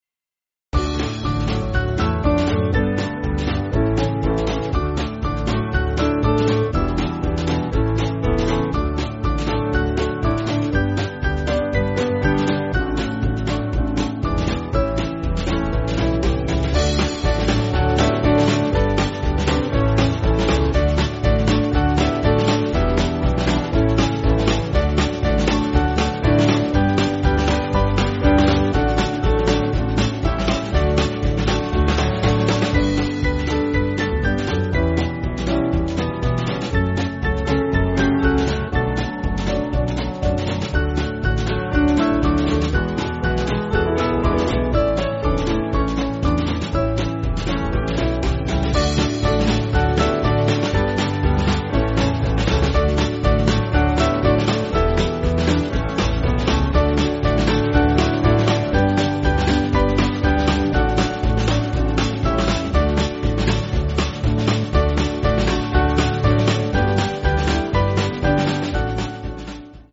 Yiddish folk tune
Small Band